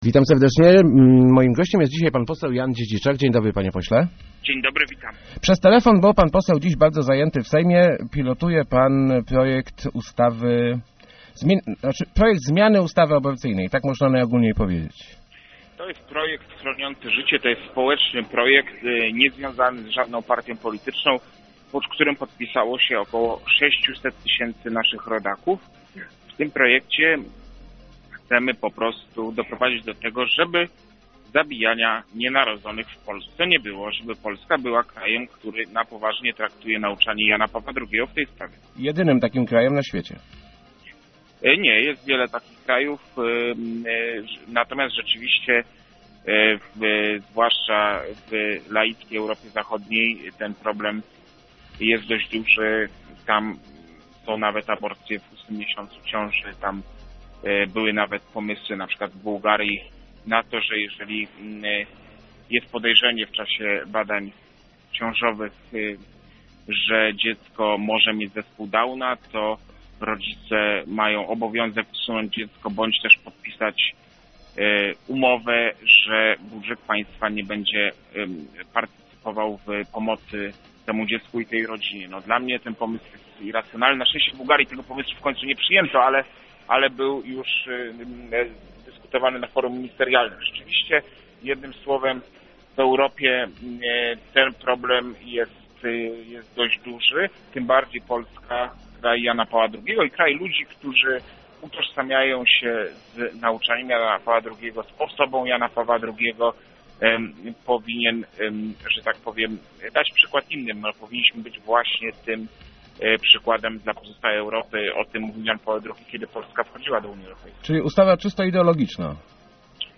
Człowiek nowoczesny z pewnością będzie przeciwnikiem aborcji - mówił w Rozmowach Elki poseł Jan Dziedziczak, prezentujący w Sejmie obywatelski projekt zmieniający ustawę aborcyjną. Zakłada on całkowity zakaz przerywania ciąży.